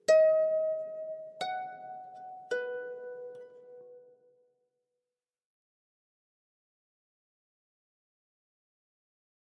Harp, Very Slow Reverberant Arpeggio, Type 1